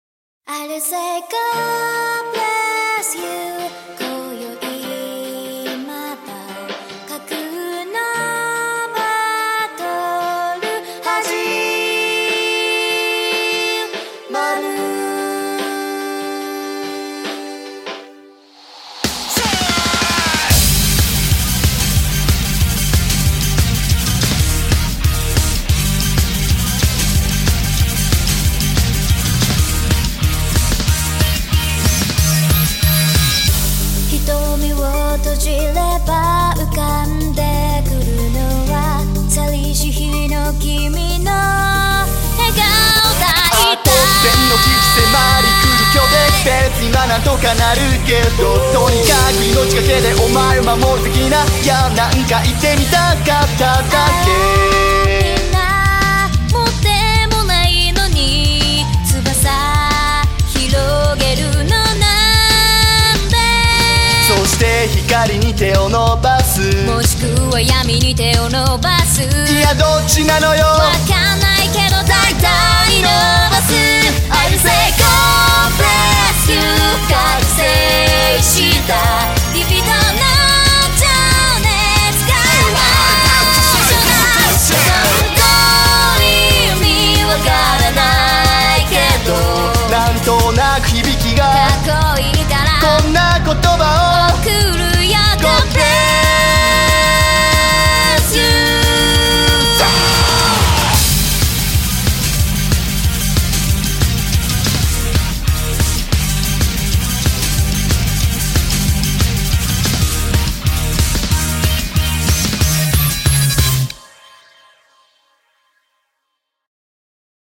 BPM145-185
Audio QualityPerfect (Low Quality)